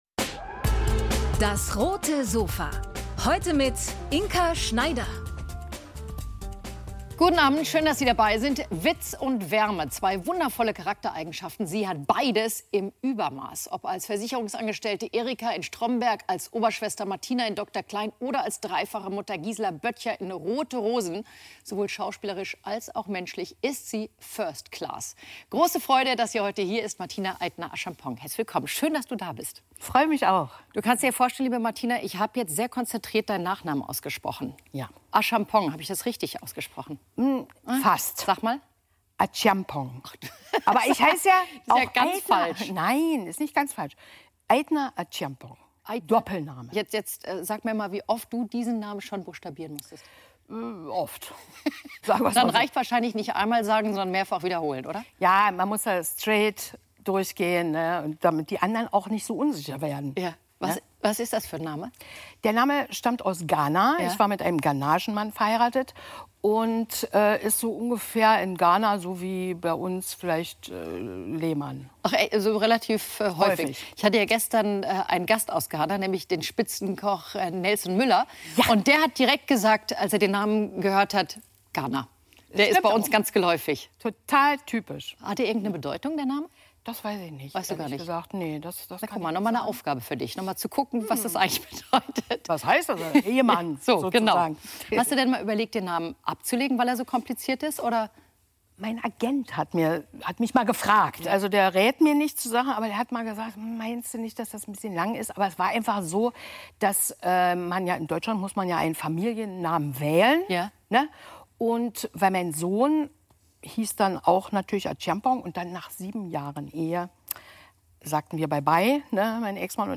Von "Stromberg" bis "Rote Rosen": Schauspielerin Martina Eitner-Acheampong ~ DAS! - täglich ein Interview Podcast